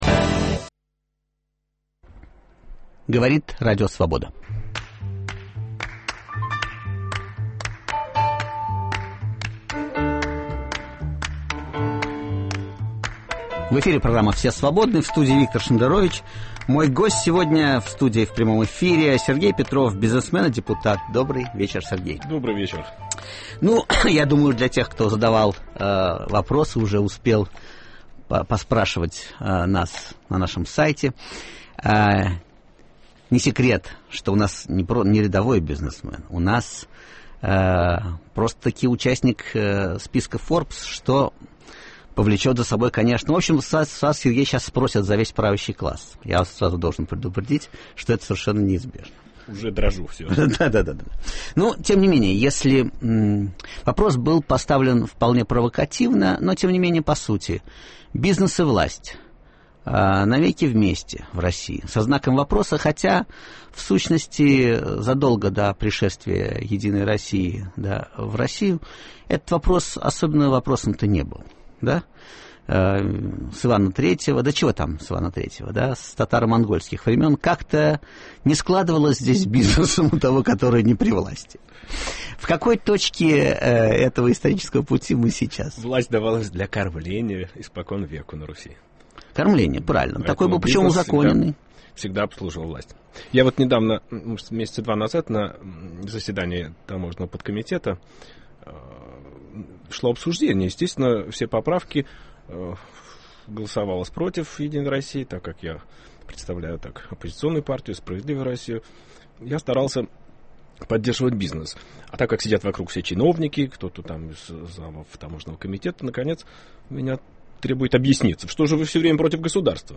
В гостях у Виктора Шендеровича – депутат и бизнесмен Сергей Петров.